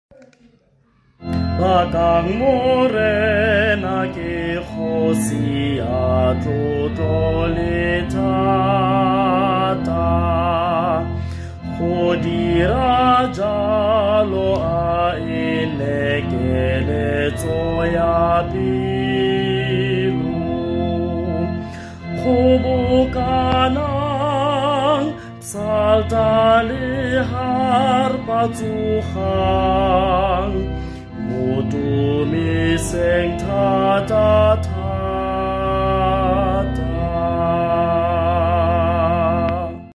Tswana Hymn 15 | Baka sound effects free download